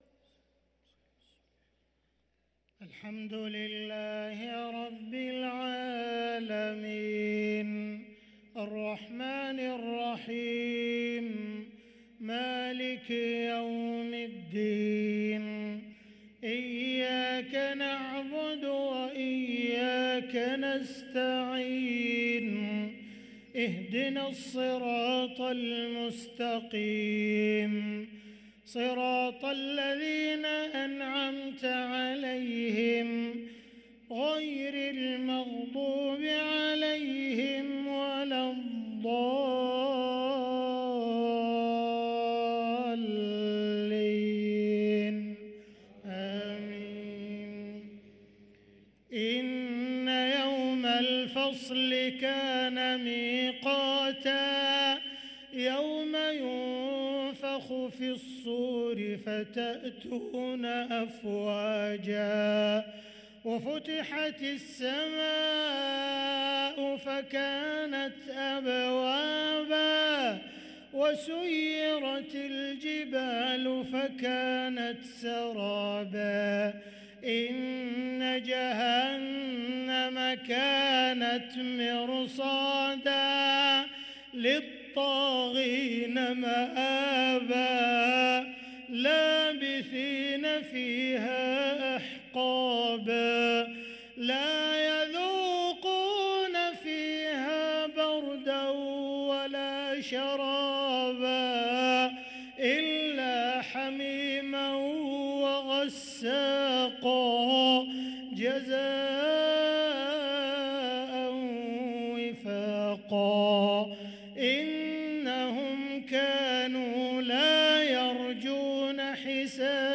صلاة العشاء للقارئ عبدالرحمن السديس 7 رجب 1444 هـ
تِلَاوَات الْحَرَمَيْن .